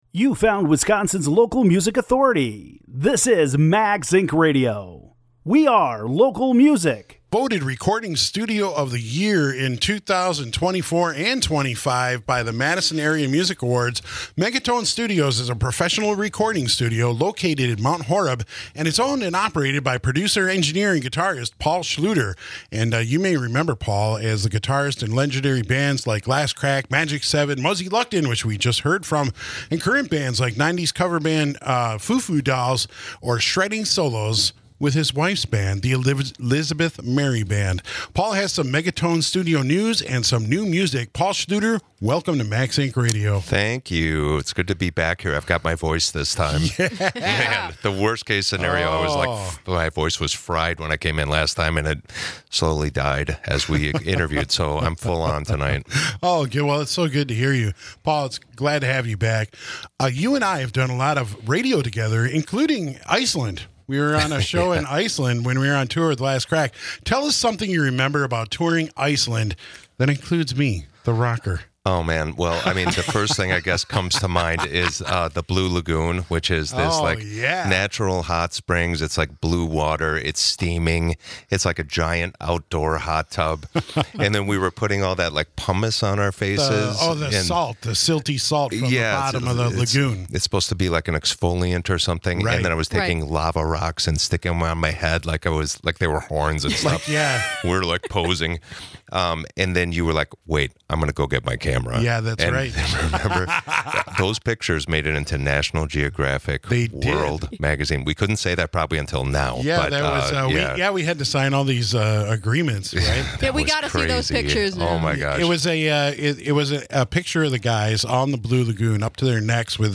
above State Street at the WMDX studio